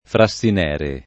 Frassinere [ fra SS in $ re ] top. (Piem.)